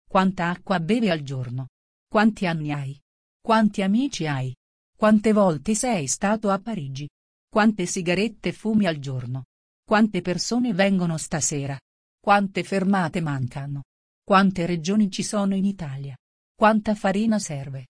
ttsMP3.com_VoiceText_2022-4-1_12_7_31